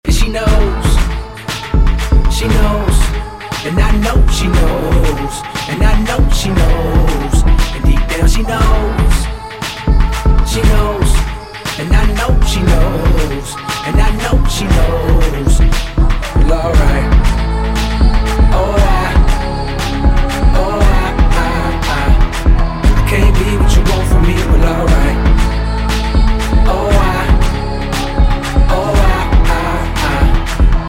мужской голос
спокойные
Rap
alternative hip hop